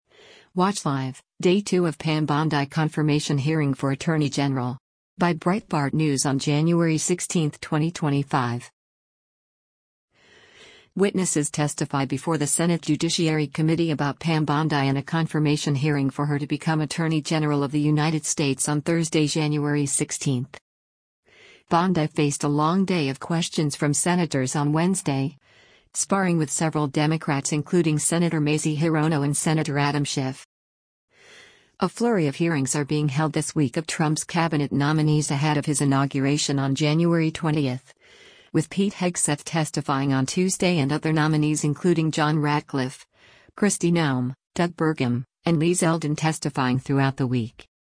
Witnesses testify before the Senate Judiciary Committee about Pam Bondi in a confirmation hearing for her to become Attorney General of the United States on Thursday, January 16.